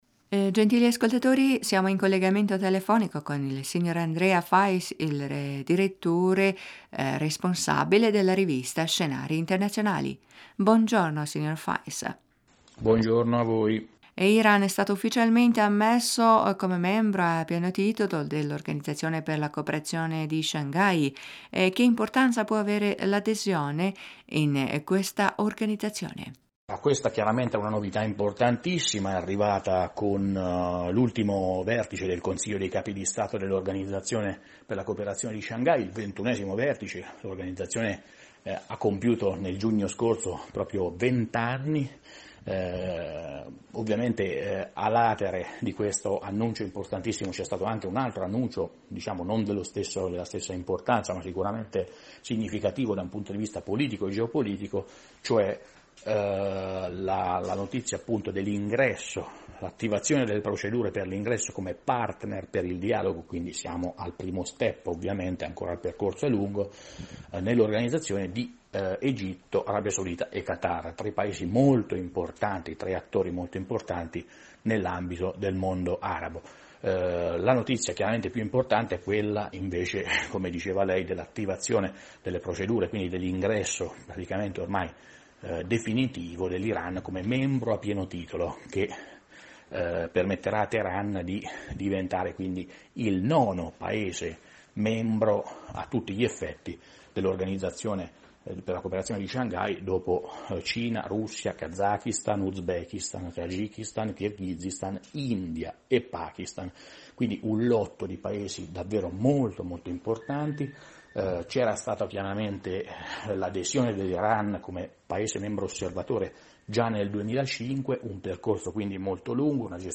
in un collegamento telefonico con la Radio Italia della voce della Repubblica islamica dell'Iran